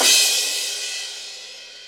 CRASH 2.wav